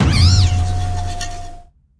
Cri de Fongus-Furie dans Pokémon HOME.